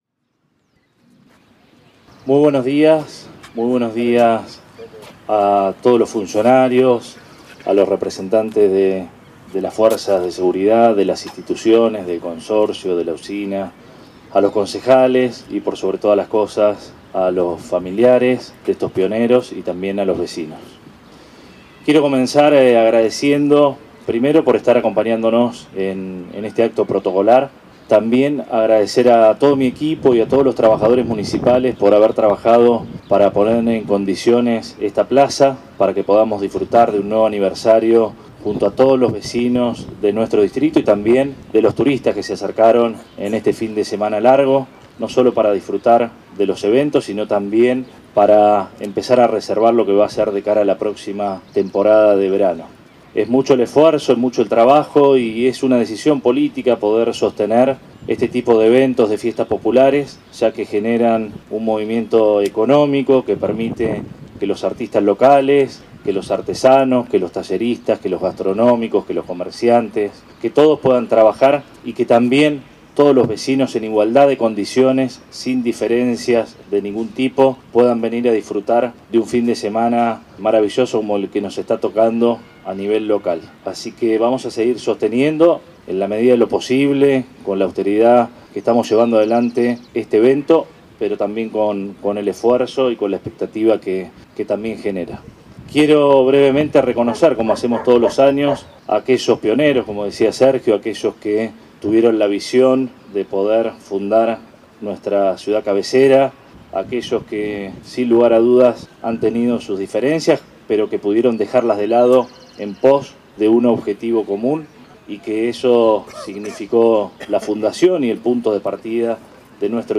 El intendente Arturo Rojas encabezó el acto oficial que sirvió para celebrar el 143º Aniversario de Necochea y rendir homenaje a sus fundadores.